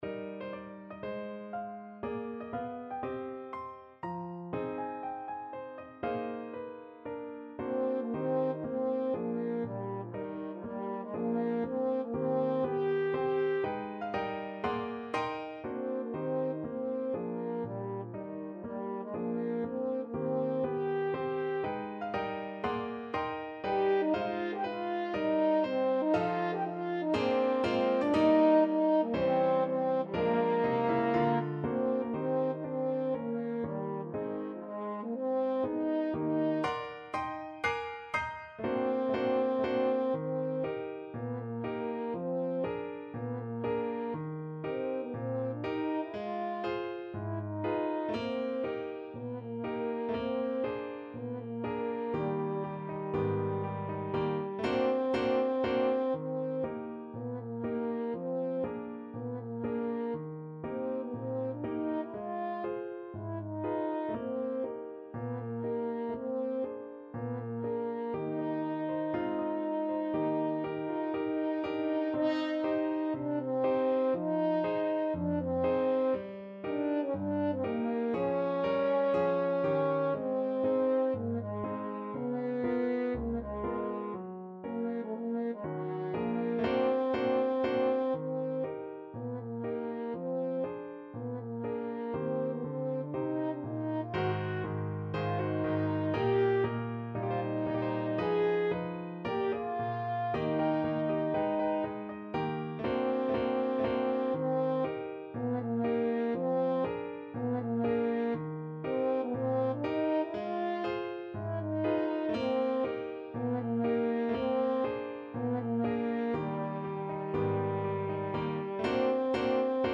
4/4 (View more 4/4 Music)
~ = 120 Moderato